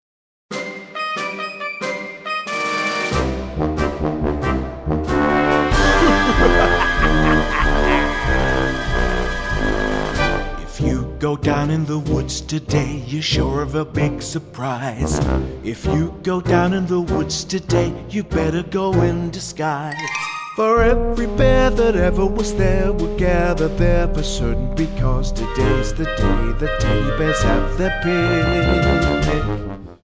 --> MP3 Demo abspielen...
Tonart:Cm Multifile (kein Sofortdownload.